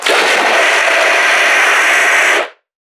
NPC_Creatures_Vocalisations_Infected [25].wav